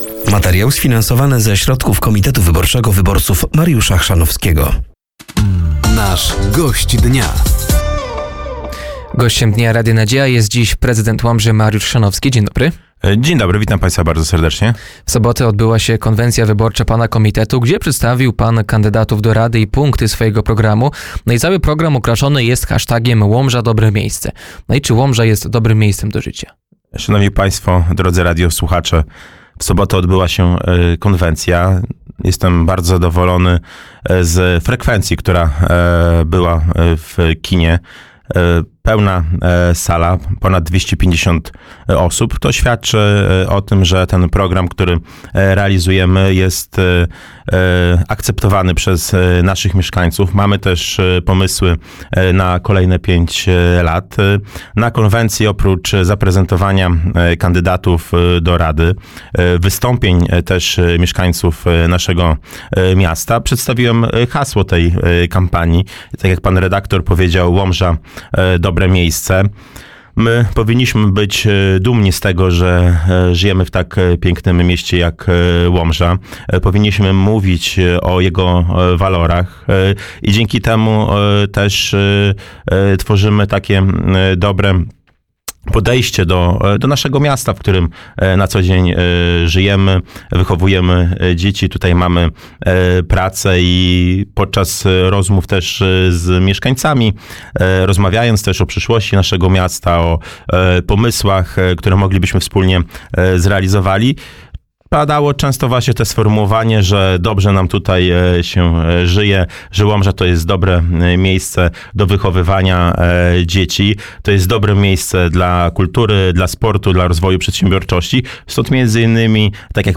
Gościem Dnia Radia Nadzieja był prezydent Łomży, Mariusz Chrzanowski.